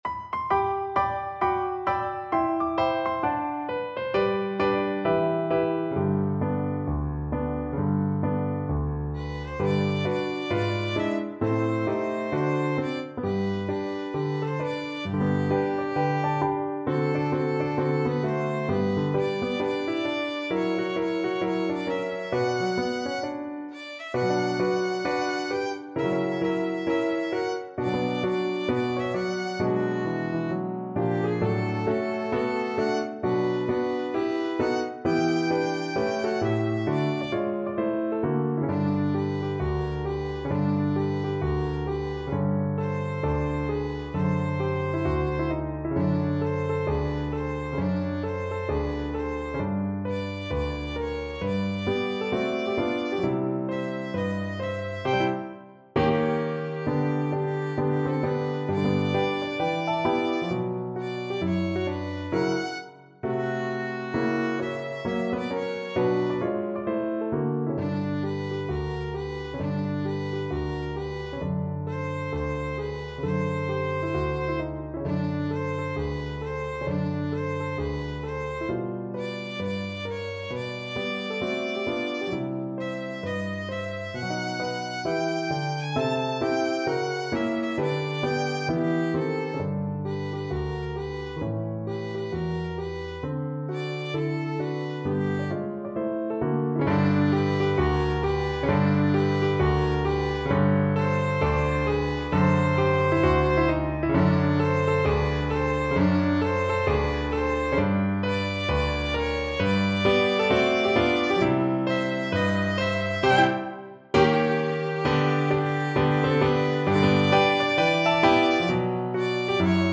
~ = 132 Moderato
2/2 (View more 2/2 Music)
Pop (View more Pop Violin Music)